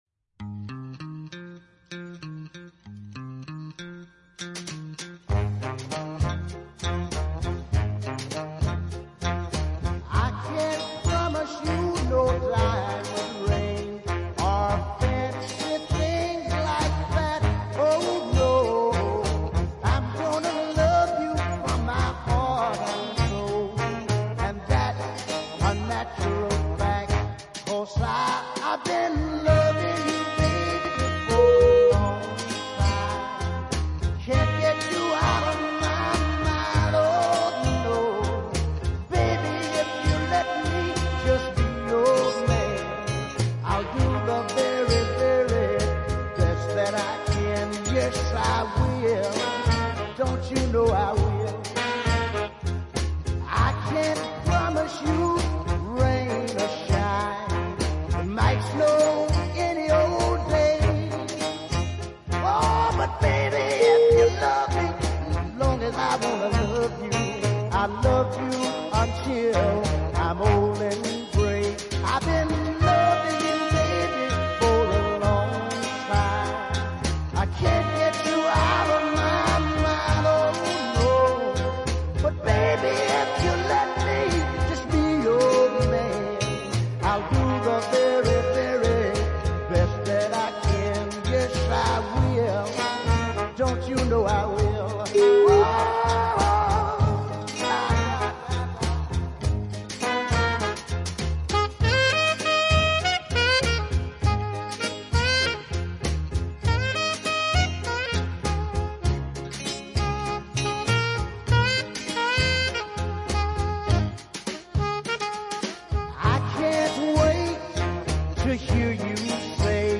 beat ballad
wonderful gruff Cooke styled delivery over a melodic rhythm